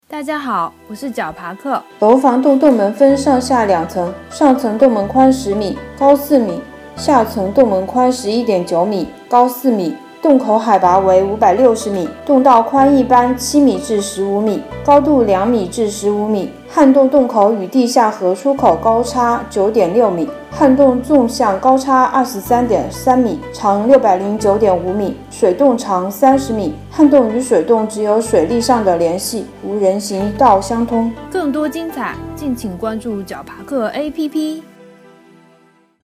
楼房洞----- fin 解说词: 楼房洞洞门分上、下两层，上层洞门宽10m，高4m，下层洞门宽11.9m，高4m。